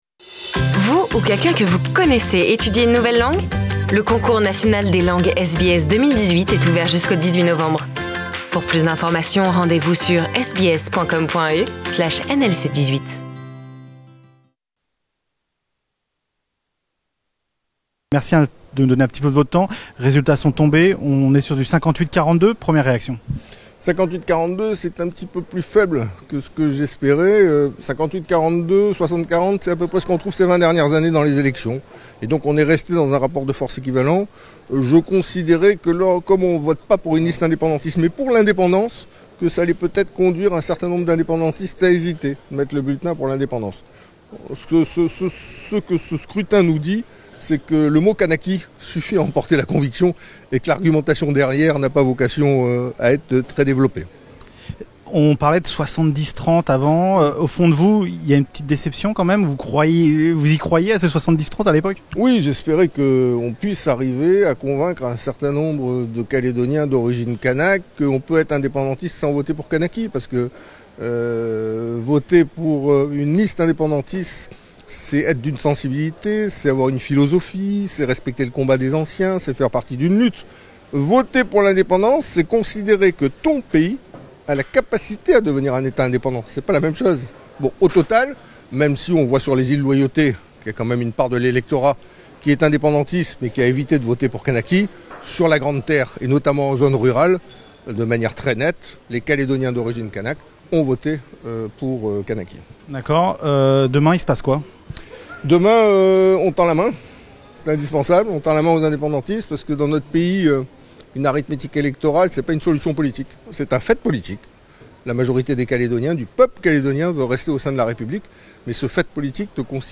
Entretien exclusif avec Philippe Gomes, leader de Calédonie Ensemble et leader du Non a l'independance